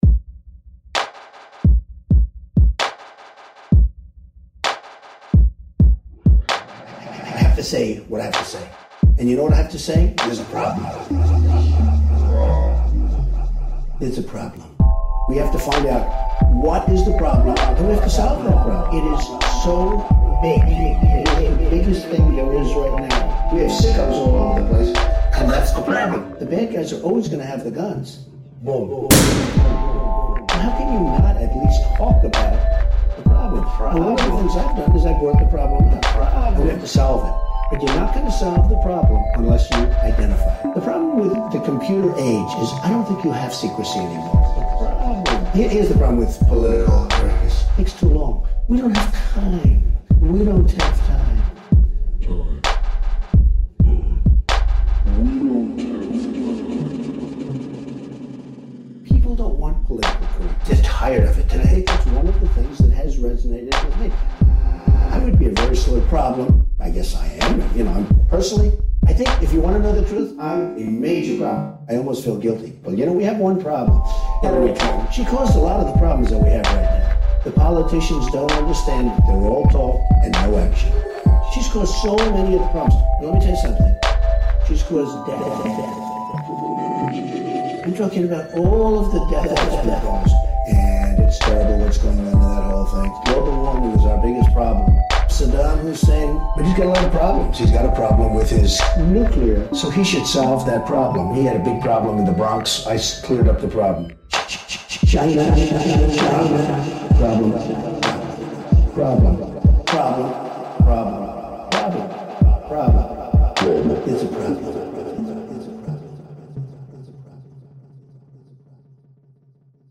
We were getting a little silly with some Trump interviews and noticed that he says "problems" a lot, which is really negative. Check out what we did to him in this experimental audio piece.